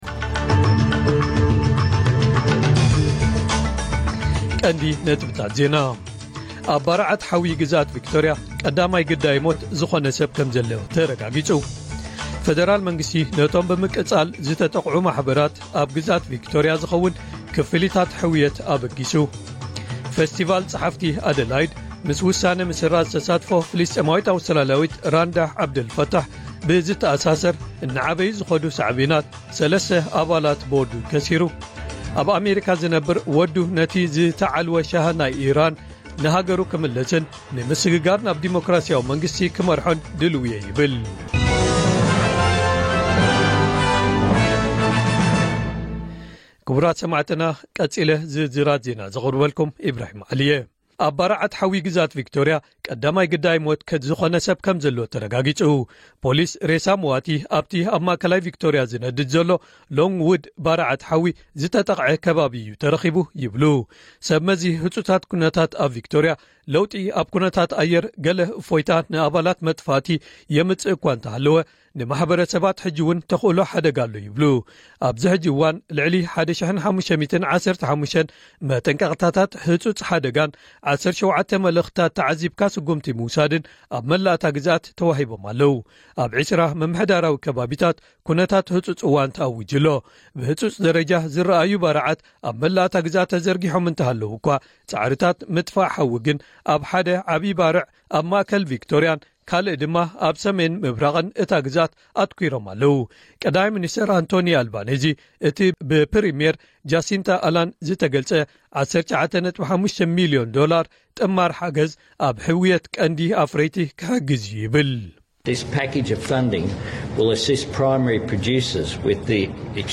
ዕለታዊ ዜና ኤስቢኤስ ትግርኛ (12 ጥሪ 2026)